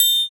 Index of /90_sSampleCDs/Spectrasonics - Supreme Beats - African Contemporary/PRC_SB Triangles/PRC_SB Triangles